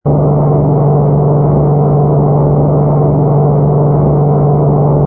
engine_ku_fighter_loop.wav